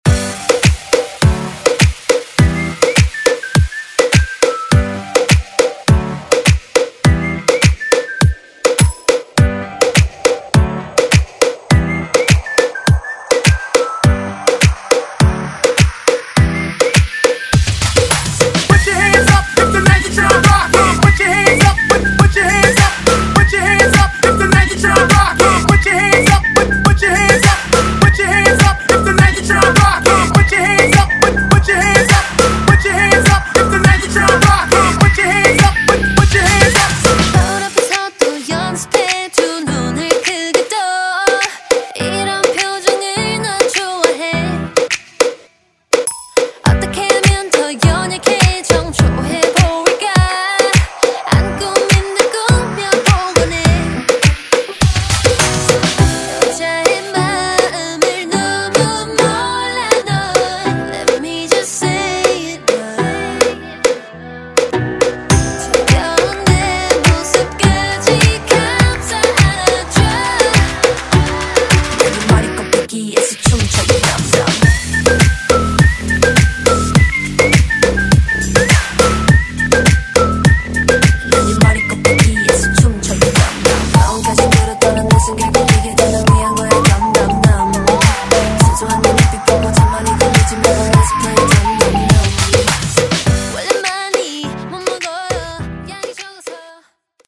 Genre: MOOMBAHTON
Clean BPM: 103 Time